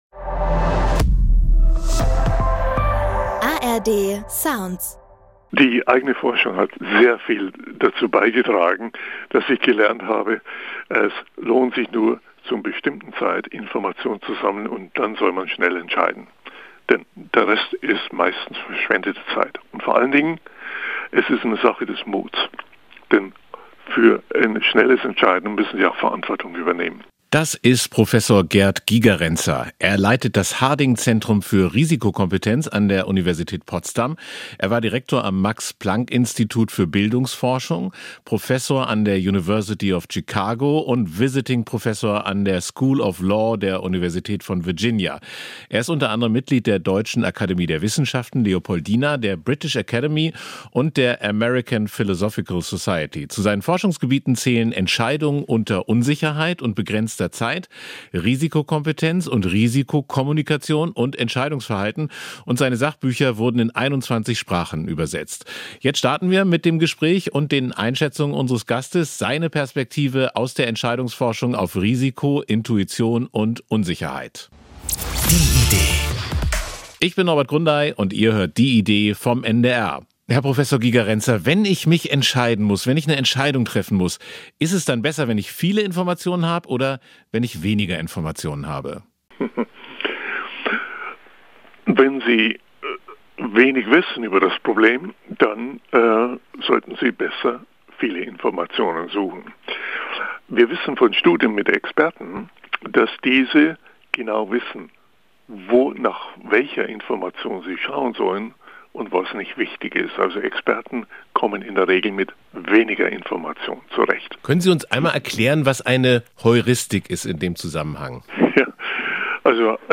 In dieser Folge spricht Prof. Gerd Gigerenzer über Entscheidungen unter Unsicherheit, Intuition und Risikokompetenz.